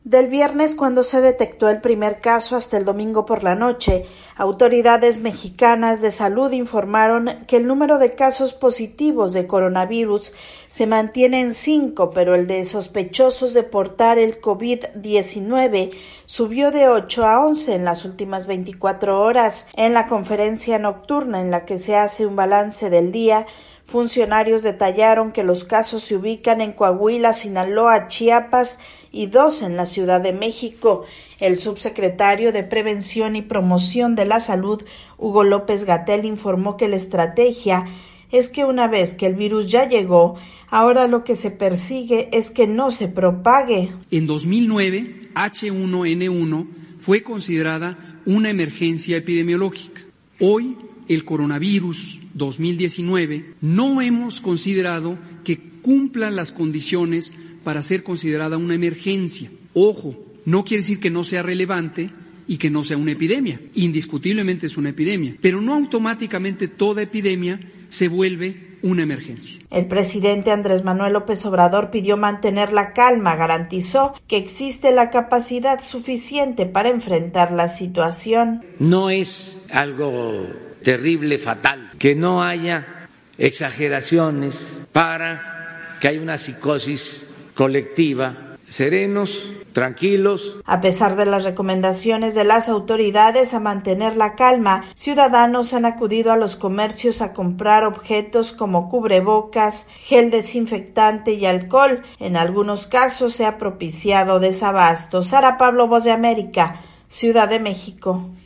VOA: Informe de México